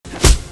punch1_1.aac